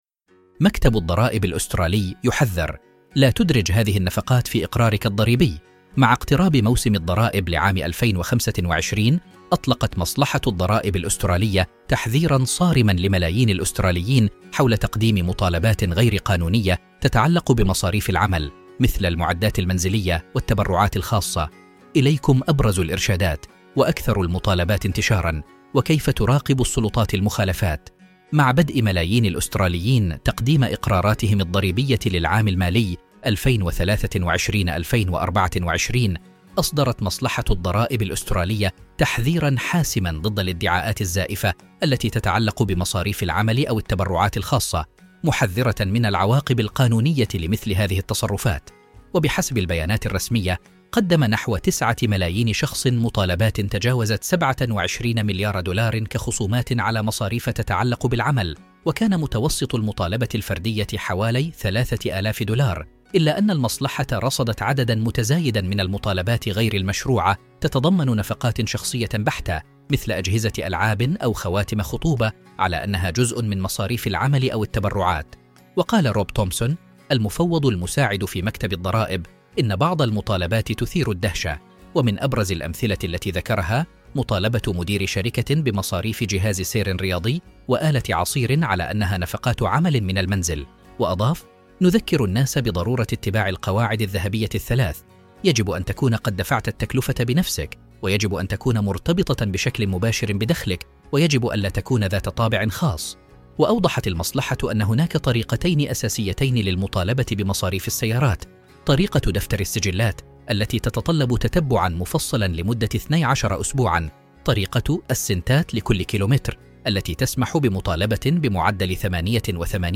الصوت المستخدم تم توليده باستخدام الذكاء الاصطناعي.